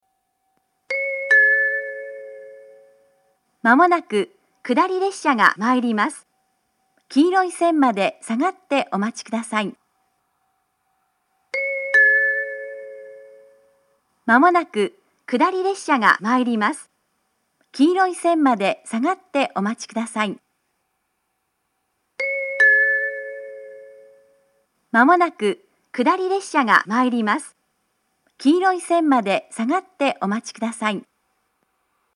狭いホームを新幹線が高速で通過することもあり、接近放送が導入されています。
下り接近放送